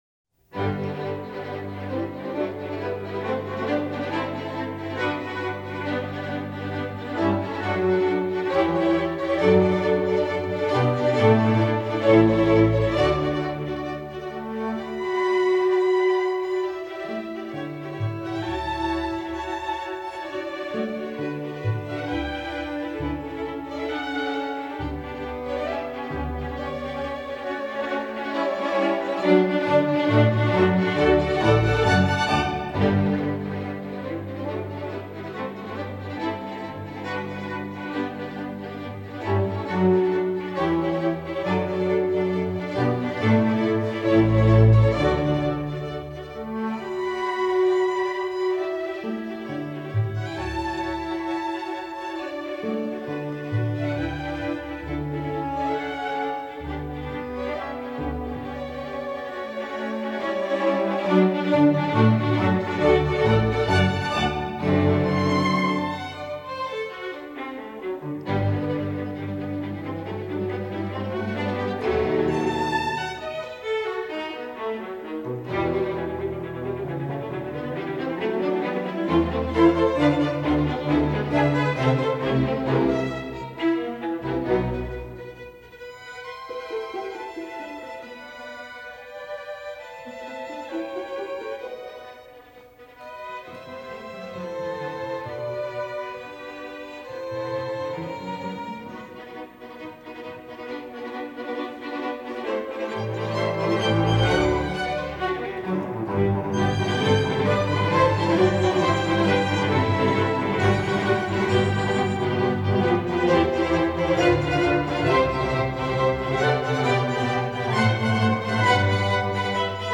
Instrumentation: string orchestra
classical